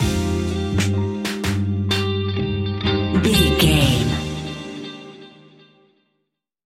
Ionian/Major
D♯
laid back
Lounge
sparse
new age
chilled electronica
ambient
atmospheric
instrumentals